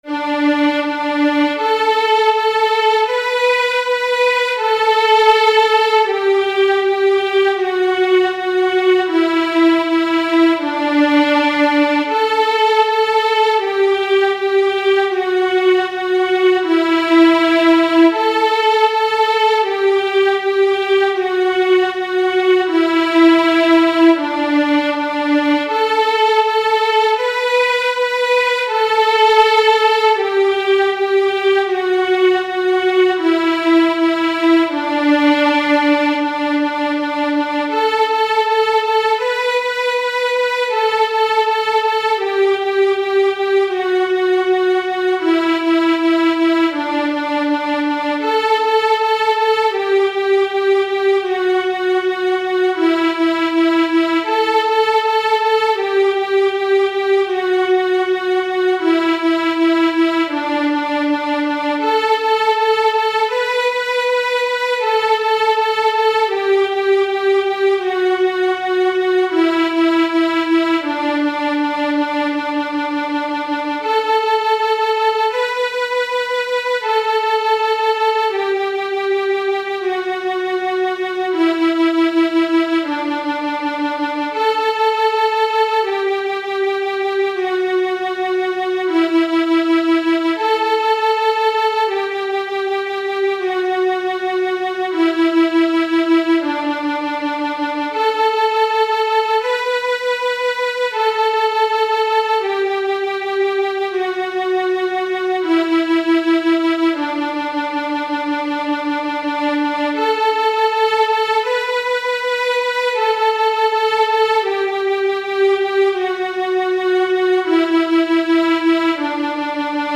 작은별 > 바이올린 | 신나요 오케스트라
반주